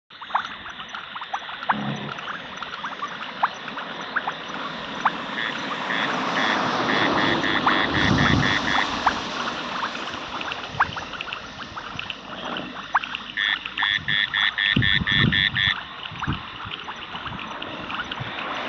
Wide-Range Fungoid Frog